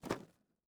sfx_猫躲起来.wav